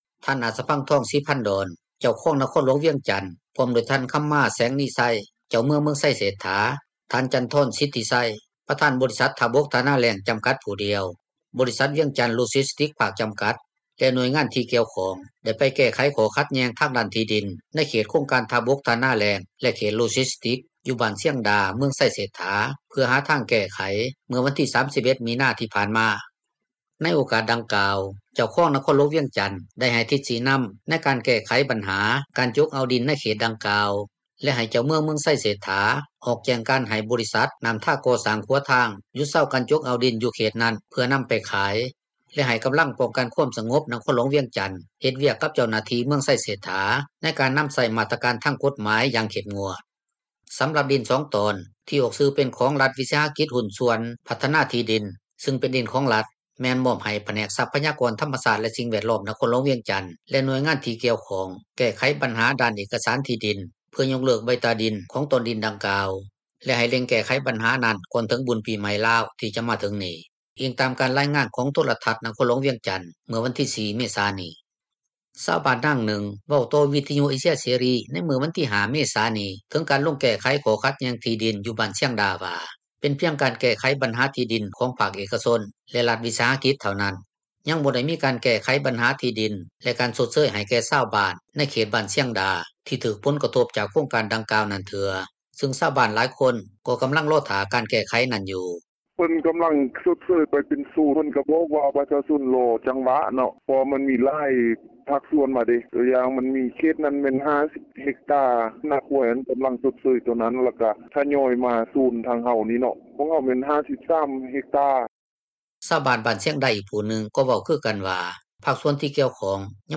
ຊາວບ້ານນາງນຶ່ງ ເວົ້າຕໍ່ວິທຍຸເອເຊັຽເສຣີ ໃນມື້ວັນທີ 5 ເມສານີ້ ເຖິງການລົງແກ້ໄຂຂໍ້ຂັດແຍ່ງ ທີ່ດິນຢູ່ບ້ານຊຽງດາ ວ່າ ເປັນພຽງການແກ້ໄຂບັນຫາ ທີ່ດິນຂອງພາກເອກກະຊົນ ແລະຣັຖວິສາຫະກິຈ ເທົ່ານັ້ນ, ຍັງບໍ່ໄດ້ມີການແກ້ໄຂ ບັນຫາທີ່ດິນ ແລະການຊົດເຊີຍ ໃຫ້ແກ່ຊາວບ້ານ ໃນເຂດບ້ານຊຽງດາ ທີ່ຈະຖືກຜົນກະທົບ ຈາກໂຄງການດັ່ງກ່າວນັ້ນເທື່ອ ຊຶ່ງຊາວບ້ານຫລາຍຄົນ ກໍາລັງລໍຖ້າການແກ້ໄຂນັ້ນຢູ່.
ຊາວບ້ານບ້ານຊຽງດາ ອີກຜູ້ນຶ່ງ ກໍເວົ້າຄືກັນວ່າ ພາກສ່ວນທີ່ກ່ຽວຂ້ອງ ຍັງບໍ່ທັນໄດ້ໄກ່ເກັ່ຍເຣື່ອງຄ່າຊົດເຊີຍ ກັບຄອບຄົວຂອງຕົນ ແລະຊາວບ້ານອີກຫລາຍຄົນເທື່ອ ແຕ່ກໍມີການກໍ່ສ້າງ ຢູ່ເຂດບ້ານຊຽງດາແລ້ວ ເພື່ອເຮັດເປັນສໍານັກງານ ຂອງໂຄງການດັ່ງກ່າວ.